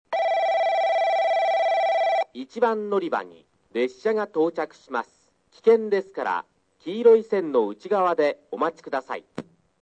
スピーカー：川崎型
音質：D
標準放送です。